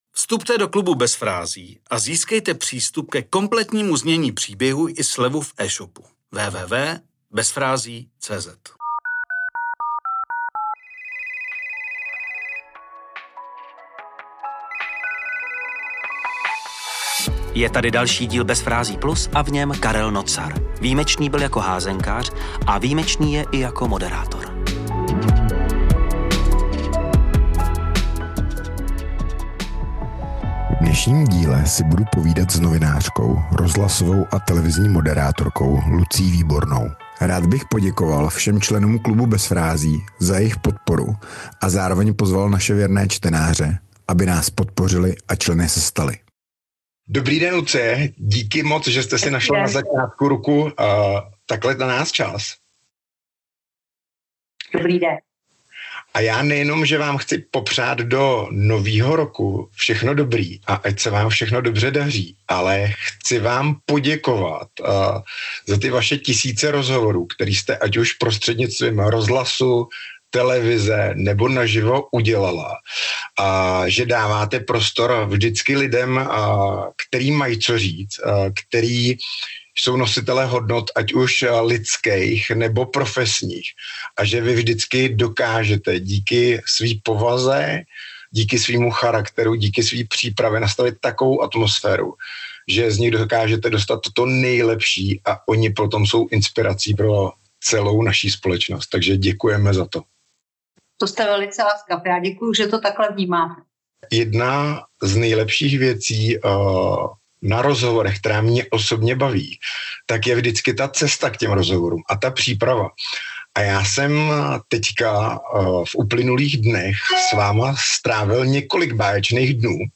🎧 CELÝ ROZHOVOR pouze pro členy KLUBU BEZ FRÁZÍ.